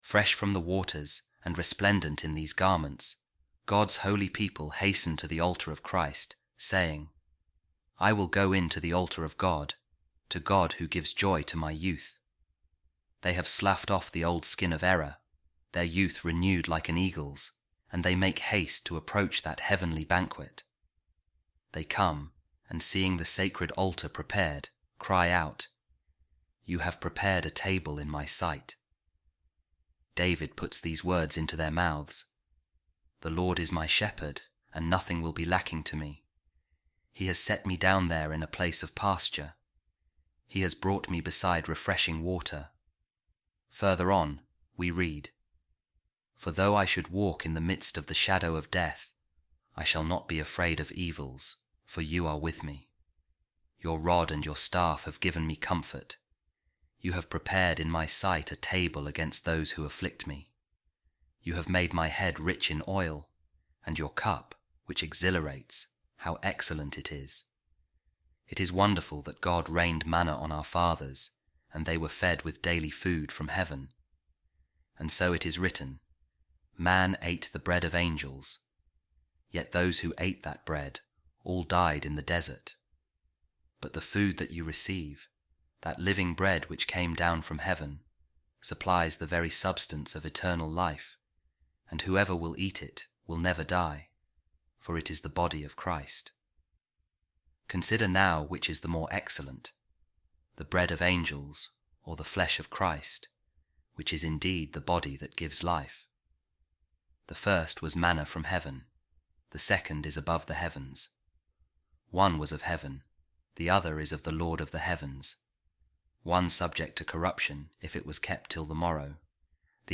Office Of Readings | Week 15, Friday, Ordinary Time | A Reading From The Treatise Of Saint Ambrose On The Mysteries | Neophytes And The Eucharist